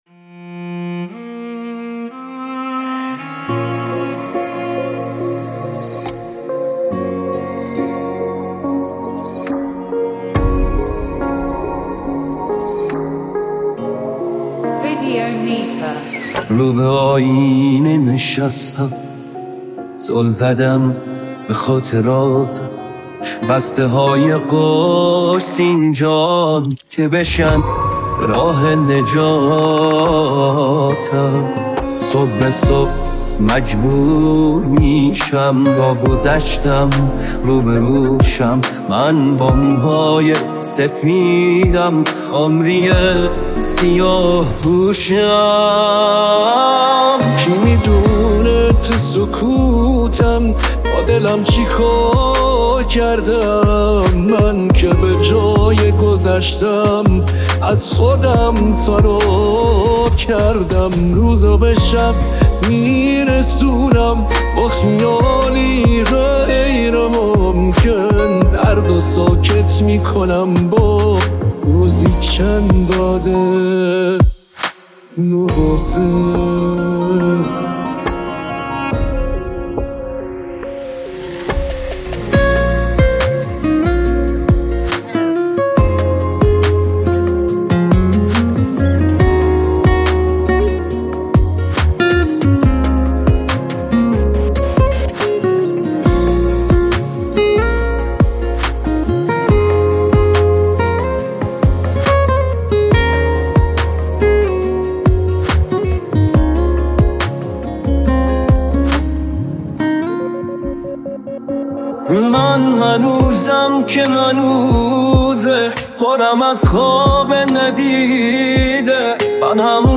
پاپ شاد عاشقانه قدیمی